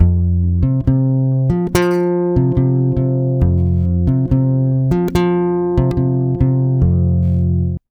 140BAS FM7 5.wav